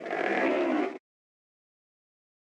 PixelPerfectionCE/assets/minecraft/sounds/mob/polarbear_baby/idle2.ogg at mc116